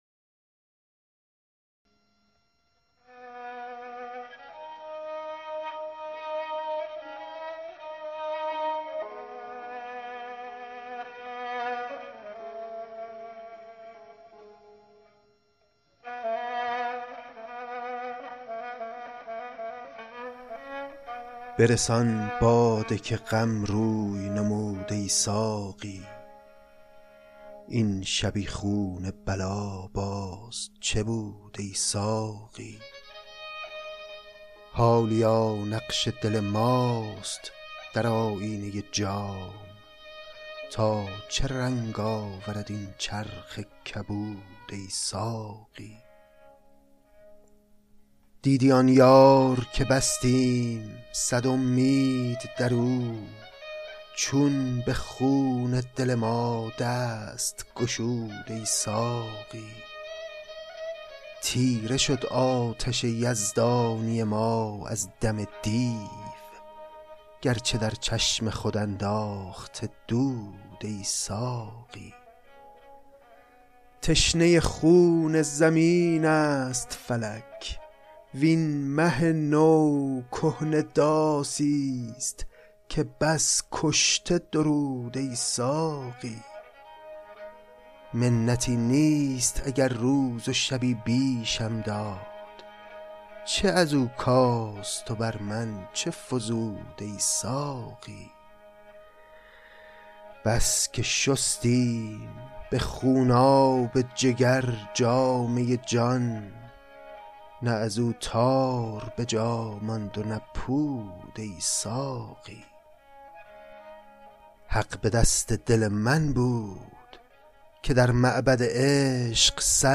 انتخاب و خوانش اشعار